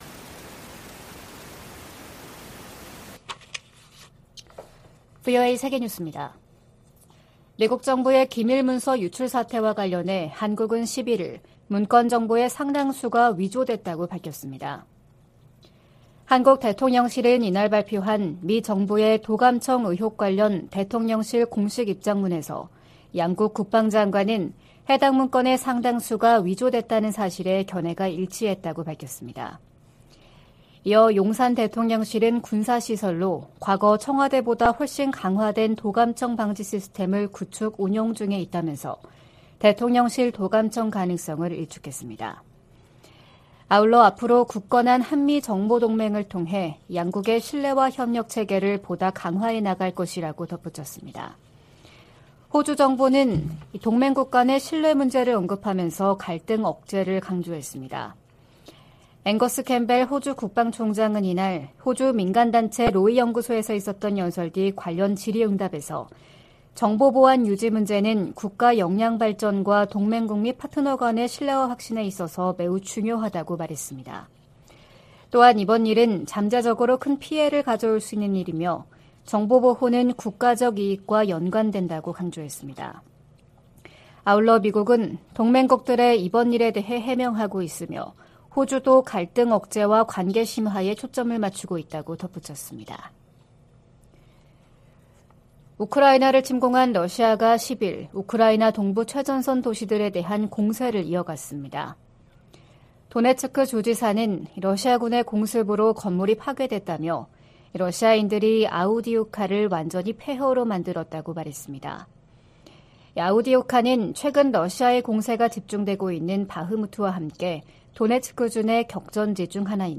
VOA 한국어 '출발 뉴스 쇼', 2023년 4월 12일 방송입니다. 미국 정부는 정보기관의 한국 국가안보실 도·감청 사안을 심각하게 여기며 정부 차원의 조사가 이뤄지고 있다고 밝혔습니다. 미국 전문가들은 이번 도청 의혹이 두 나라 간 신뢰에 문제가 발생했다고 평가하면서도, 다가오는 미한 정상회담에 큰 영향은 없을 것으로 내다봤습니다. 김정은 북한 국무위원장이 인민군 지휘관들에게 핵 무력을 공세적이고 효과적으로 운용하라고 강조했습니다.